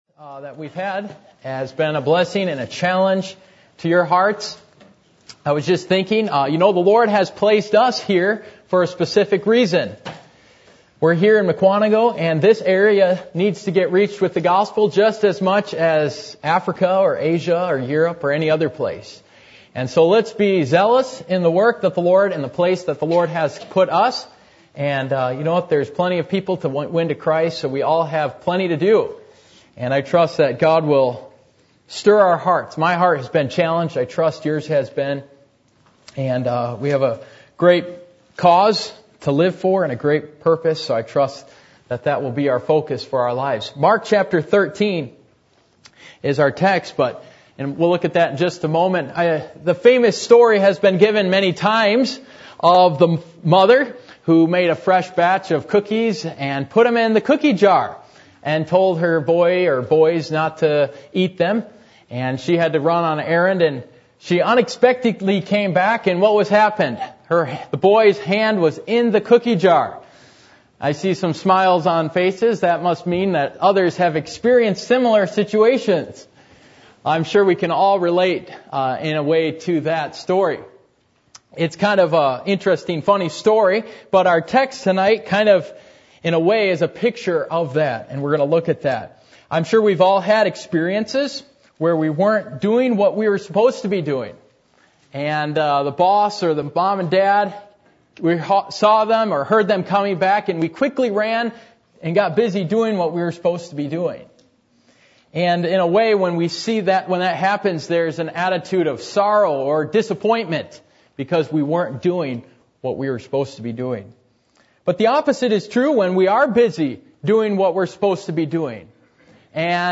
Service Type: Missions Conference